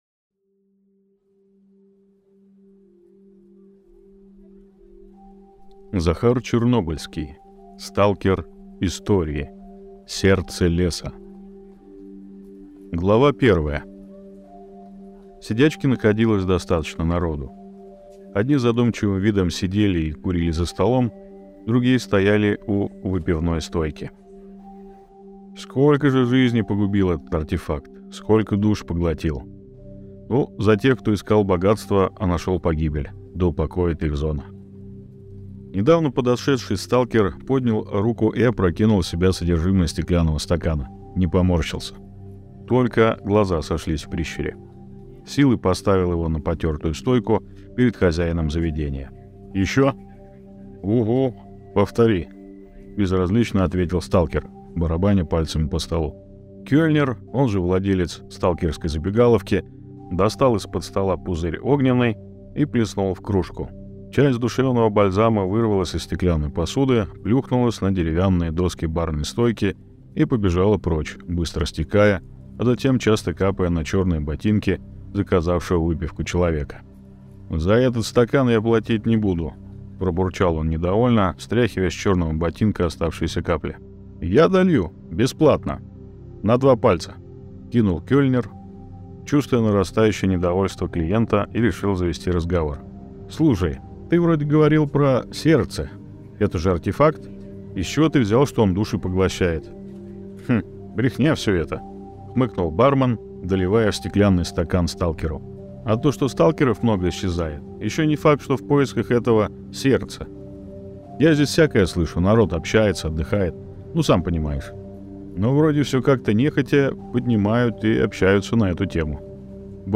Аудиокнига Сталкер. Истории. Сердце Леса | Библиотека аудиокниг